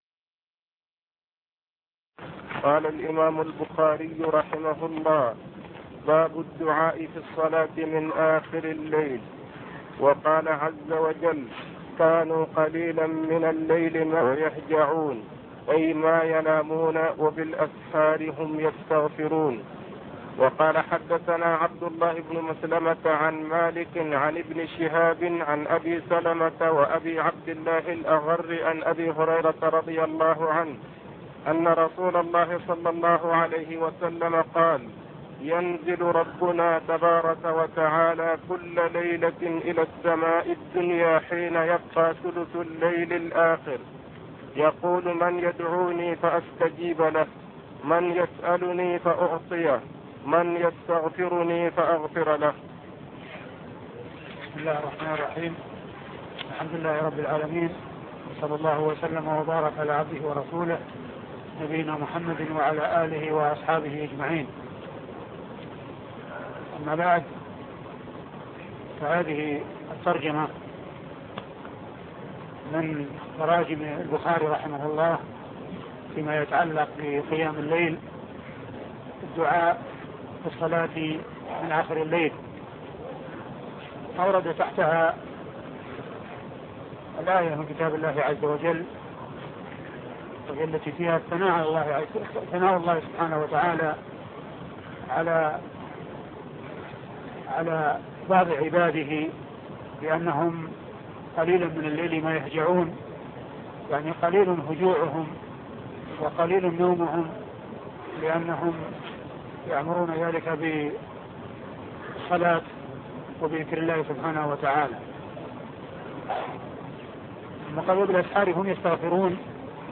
شرح صحيح البخاري الدرس عدد 142